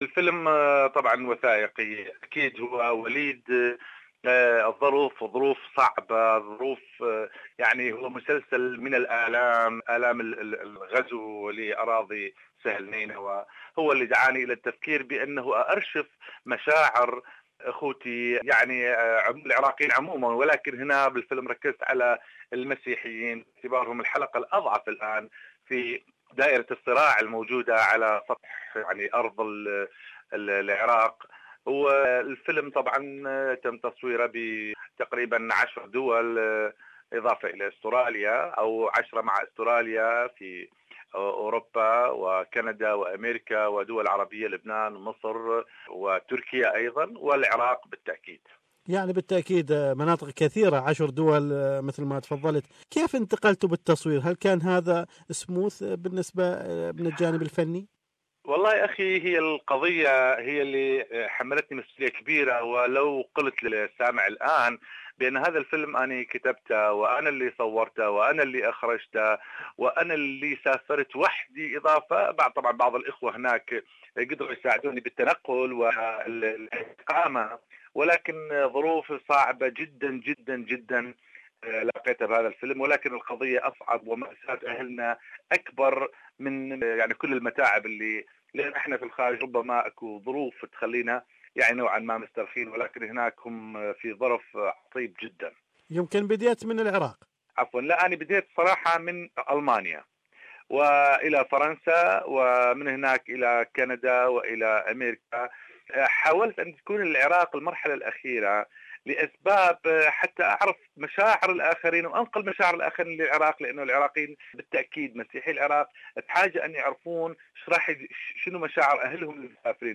The documentary records the Iraqi Christians migration out of Iraq after 2014 when IS occupied Nineveh Plains. More is in the interview